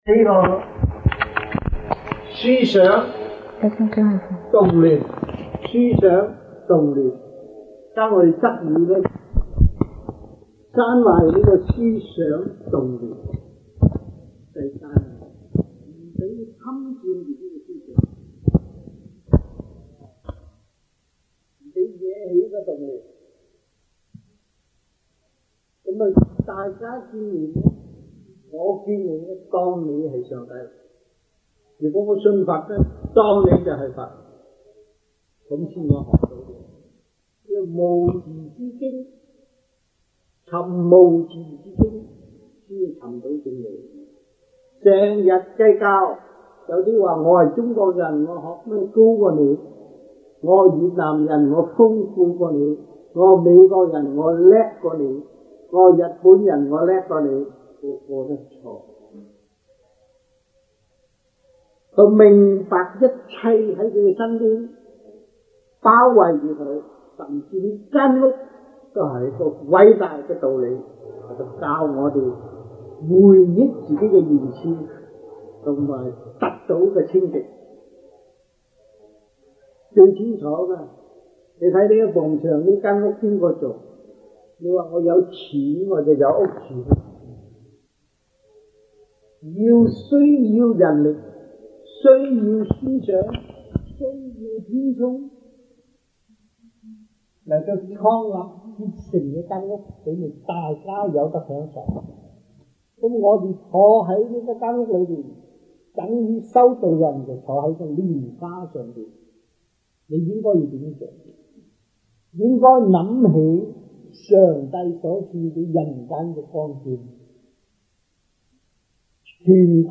Lectures-Chinese-1982 (中文講座)